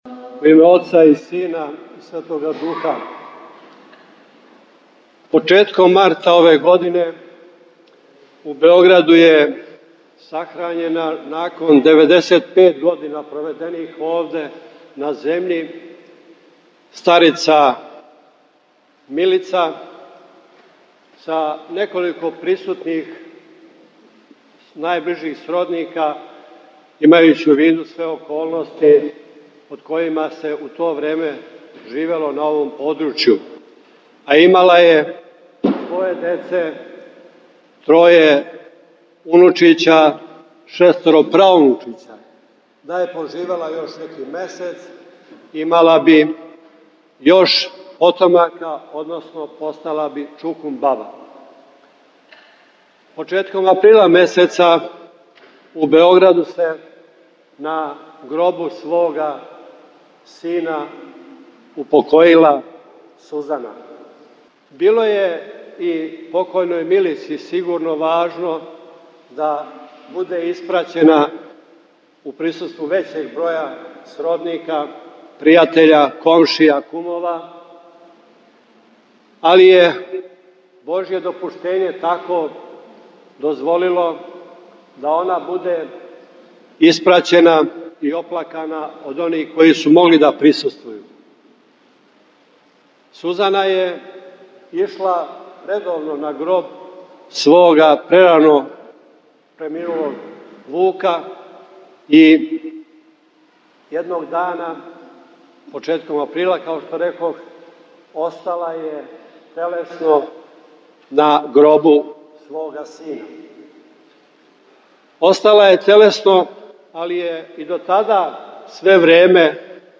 Звучни запис беседе Дајући животне примере пожртвованих верујућих жена